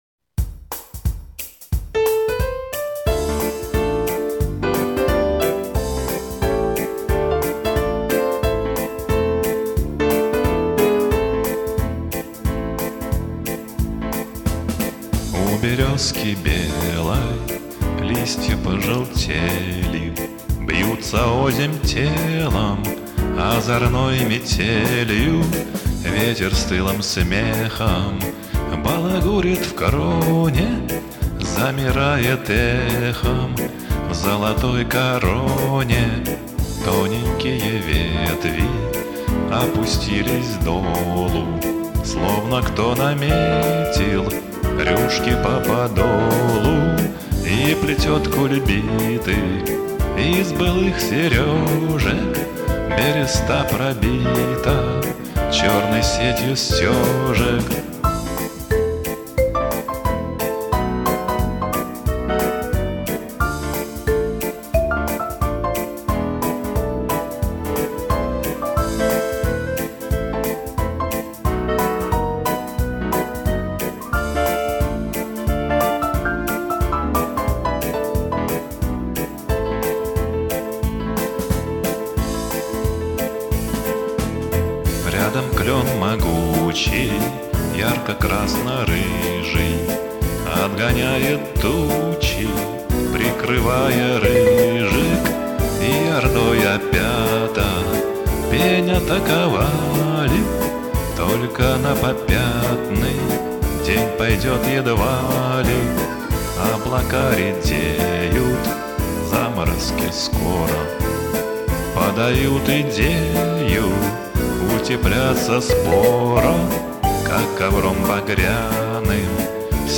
спокойная, душевная